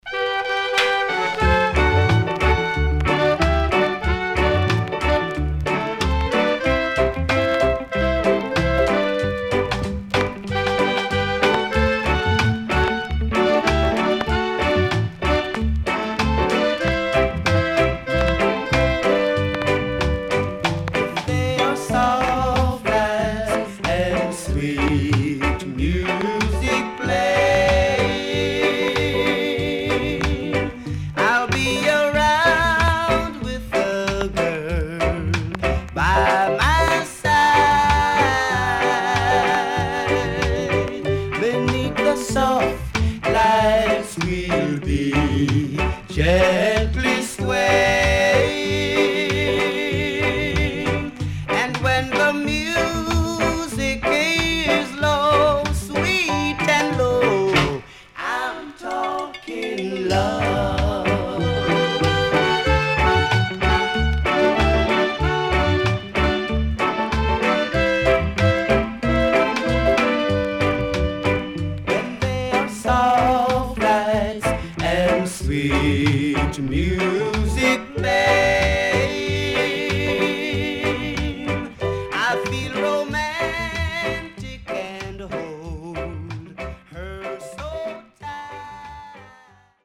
Sweet Rock Steady & Jamaican Funky Soul